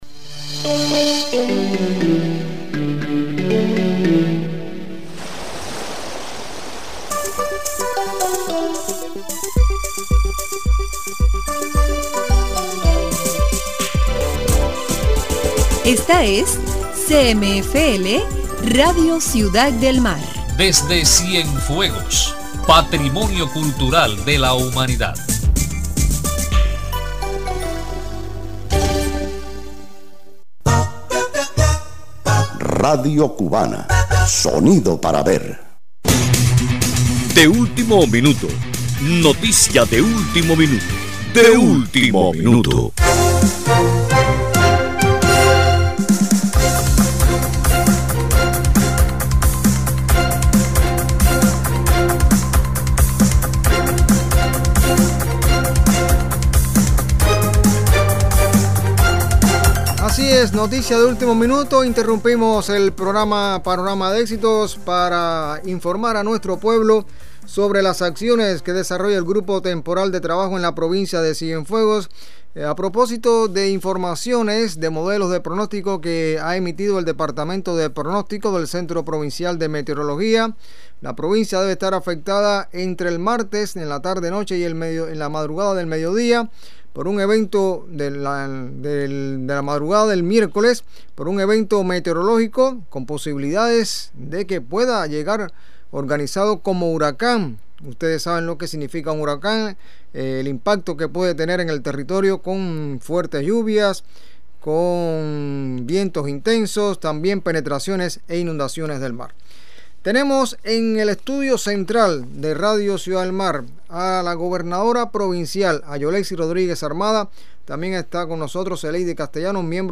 🎧 Gobernadora Provincial intervino en la radio para informar sobre medidas en Cienfuegos ante efectos de organismo tropical
Escuche la intervención de Yolexis Rodríguez Armada, Gobernadora Provincial este domingo en Radio Ciudad del Mar, acerca de las medidas que adopta el territorio ante la posible afectación de un ciclón tropical en las próximas 48 horas.